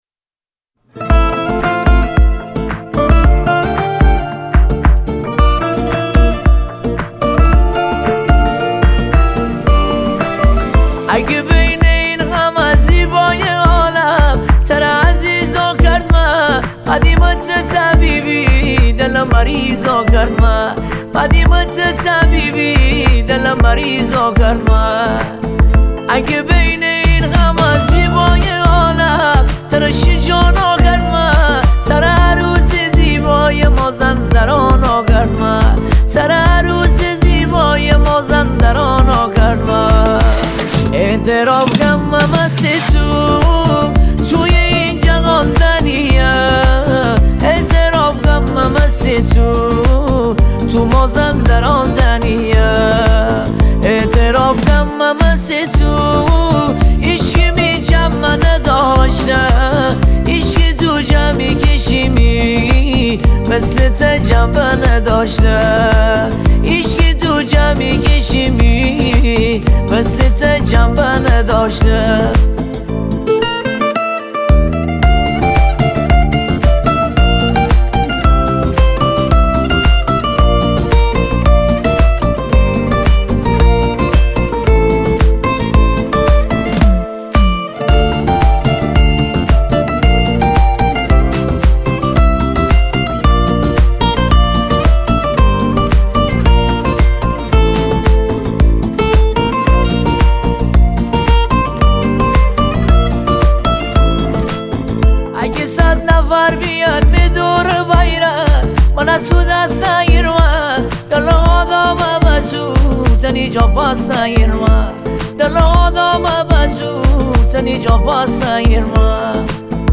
ریتمیک ( تکدست )
ریتمیک
با سبک ریتمیک مازندرانی